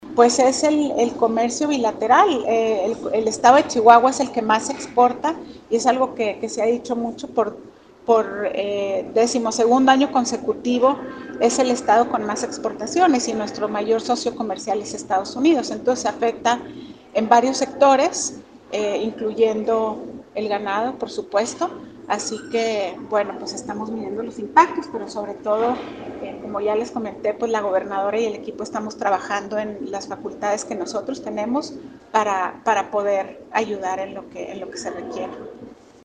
AUDIO: MARÍA ANGÉLICA GRANADOS, SECRETARÍA DE INNOVACIÓN Y DESARROLLO ECONÓMICO